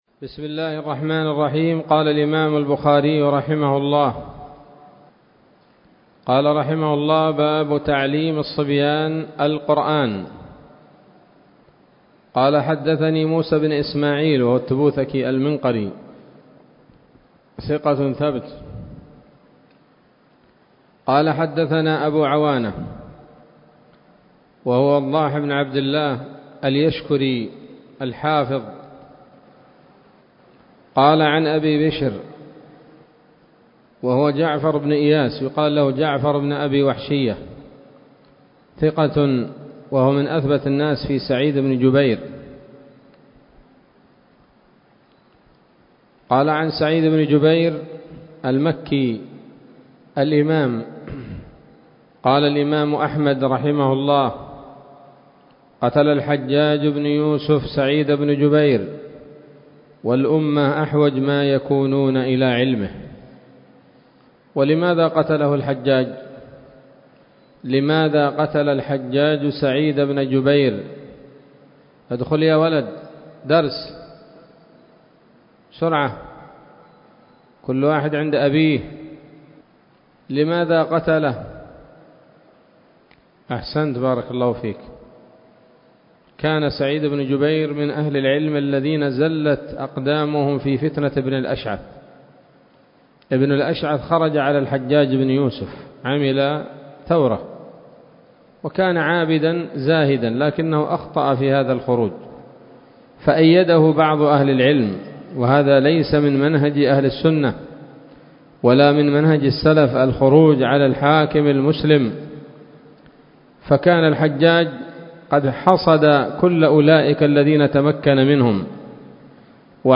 الدرس السادس والعشرون من كتاب فضائل القرآن من صحيح الإمام البخاري